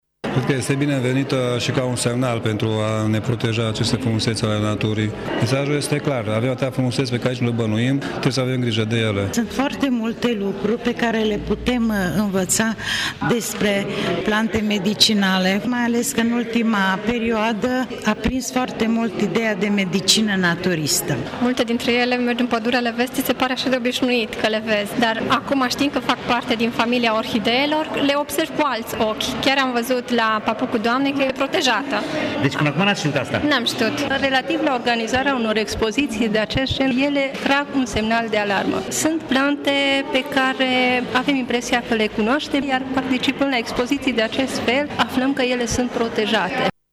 Cei prezenți la eveniment au spus că mesajul expoziției este clar: trebuie să ne protejăm frumusețile naturii, acestea având atât o valoare științifică și estetică, cât și terapeutică: